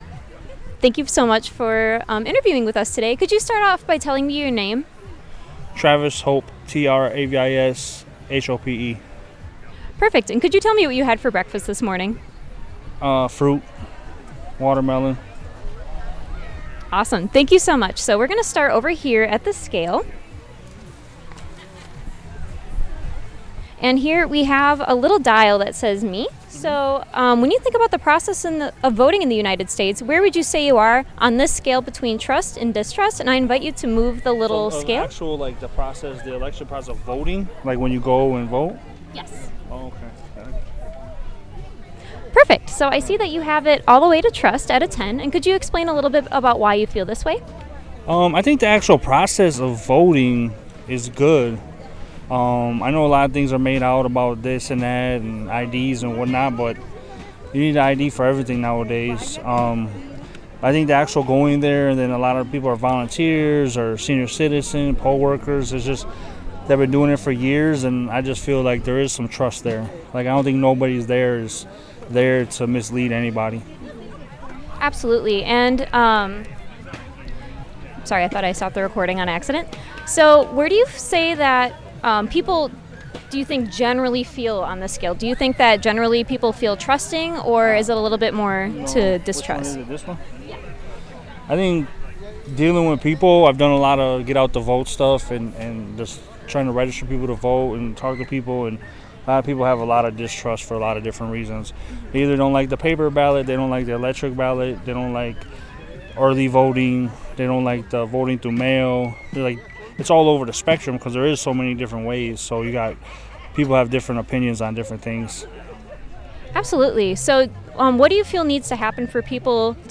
VIA Ice Cream Social